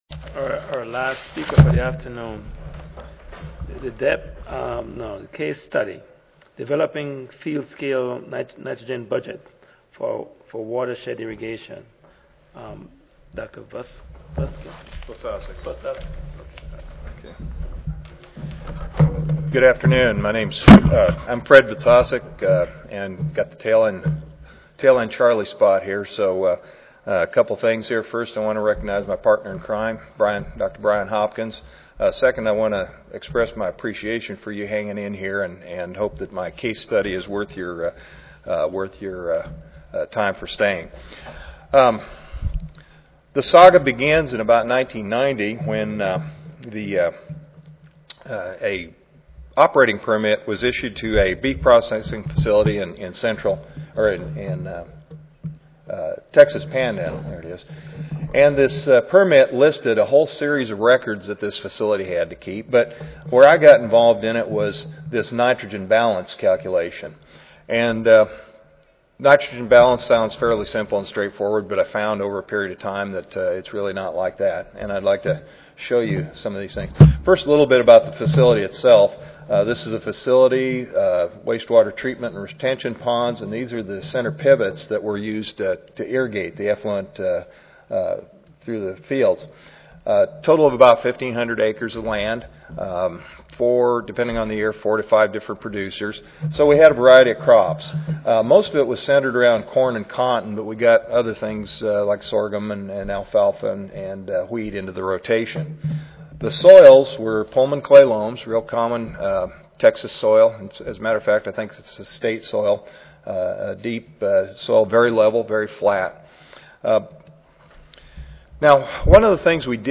Brigham Young University Recorded Presentation Audio File